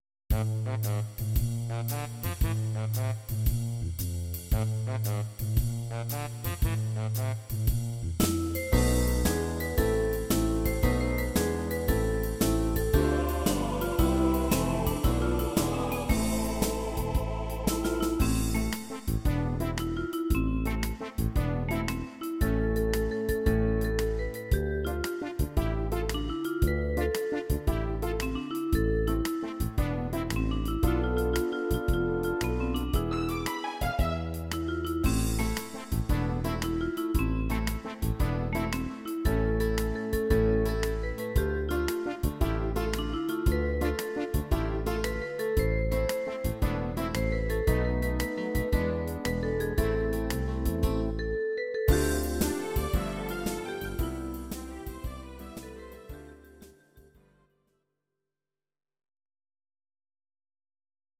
Audio Recordings based on Midi-files
Pop, Oldies, Ital/French/Span, 1950s